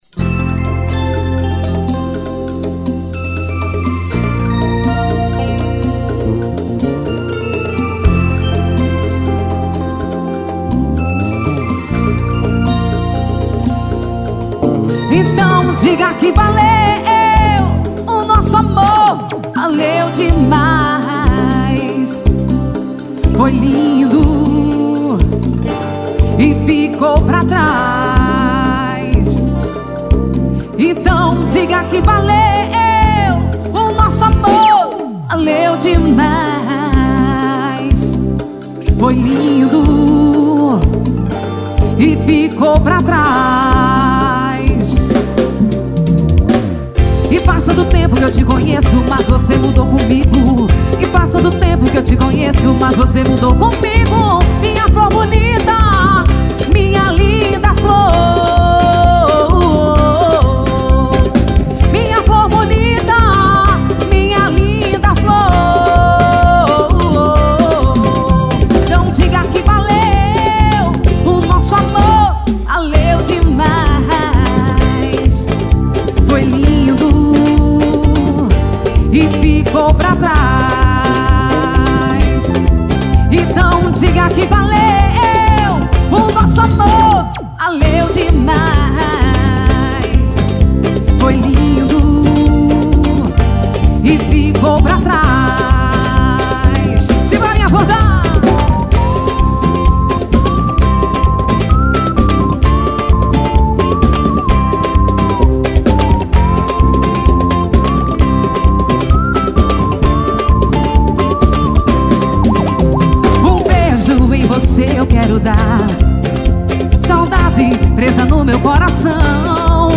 axe.